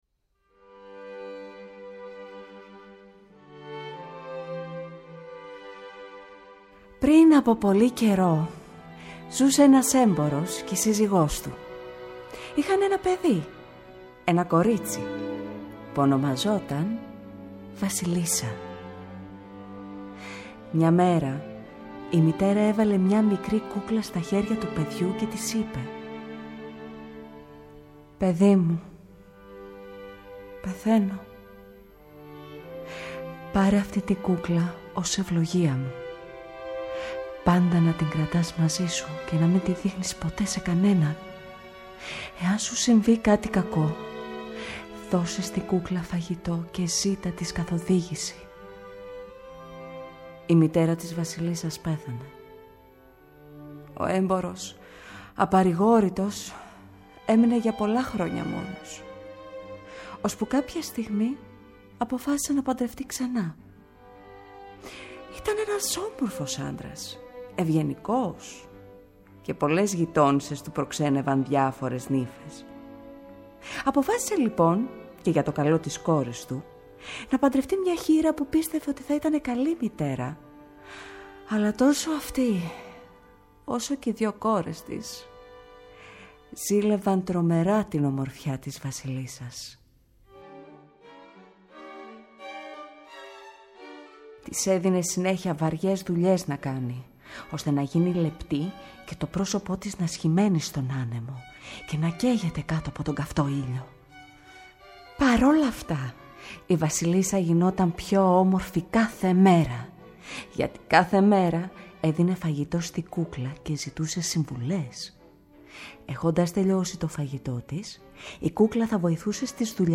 Εθνική Συμφωνική Ορχήστρα της ΕΡΤ
Μια συνομιλία μεταξύ του «Παιδικού Άλμπουμ» του Π. Ι. Τσαϊκόφσκι σε διασκευή για ορχήστα εγχόρδων, με αφηγήσεις Ρωσσικών παραμυθιών από την ηθοποιό
studio B, ΕΡΤ